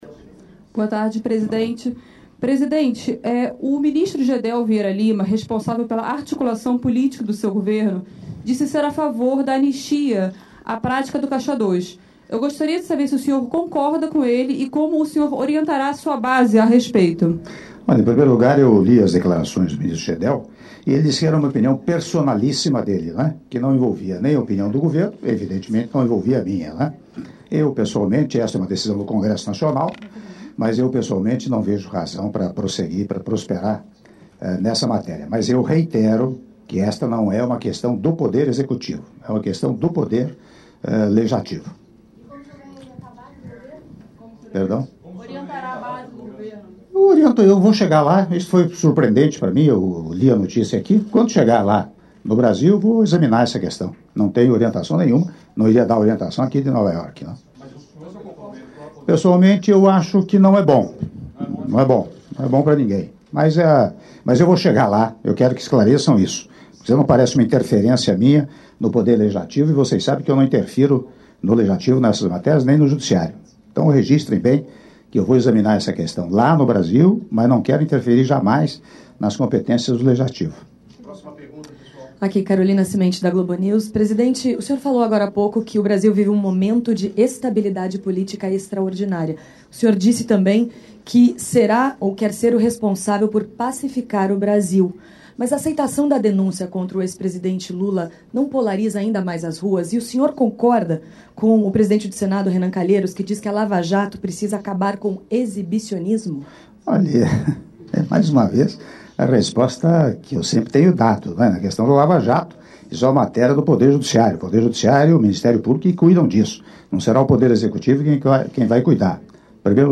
Áudio da entrevista coletiva concedida pelo presidente da República, Michel Temer, durante encontro com imprensa brasileira e internacional - Nova Iorque/EUA - (06min35s)